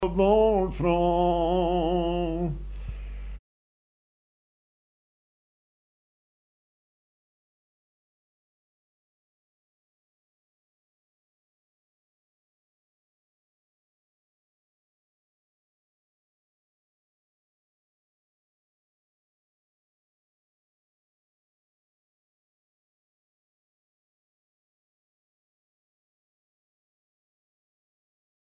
Enquête C.G. Haute-Savoie
Pièce musicale inédite